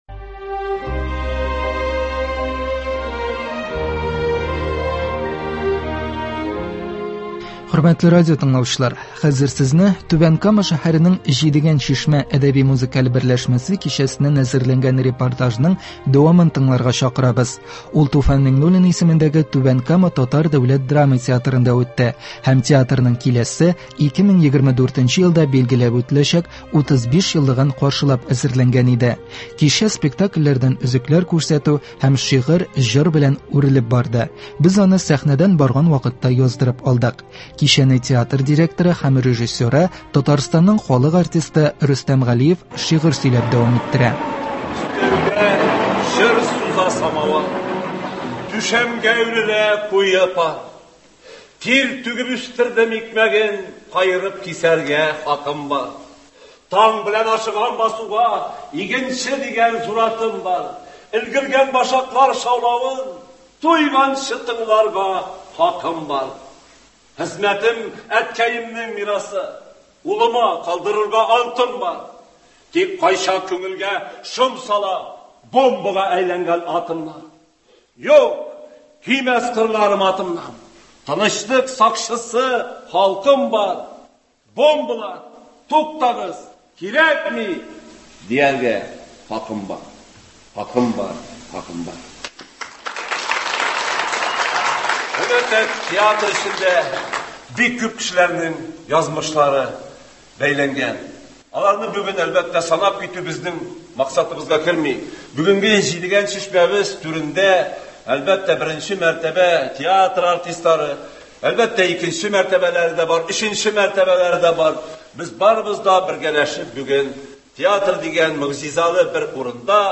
Туфан Миңнуллин исемендәге Түбән Кама татар дәүләт драма театрында шәһәрнең “Җидегән чишмә” дип аталган әдәби-музыкаль иҗат берләшмәсенең чираттагы кичәсе булып үтте. «Үткән көннәр тавышы» дип аталган кичә театрның 35 еллык юбилеен каршылап әзерләнгән иде. Әлеге күркәм чарада без дә булдык һәм бүген игътибарыгызга әлеге кичәнең язмасын тәкъдим итәбез.